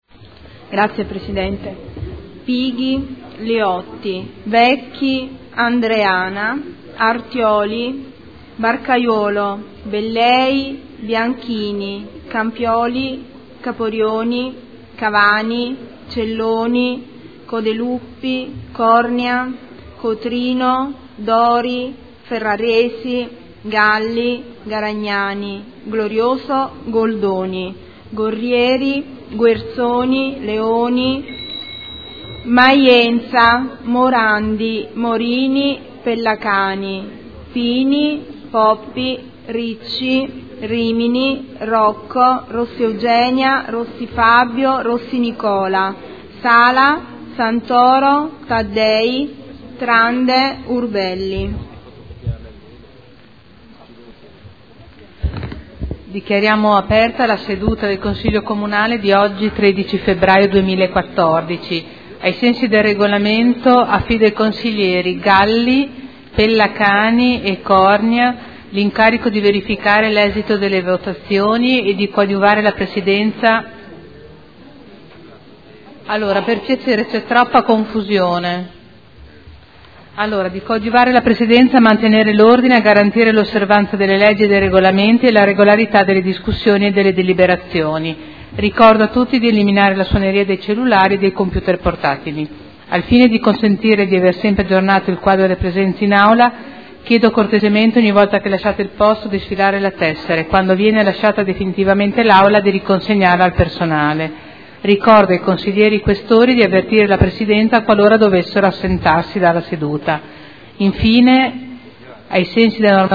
Seduta del 13 febbraio. Appello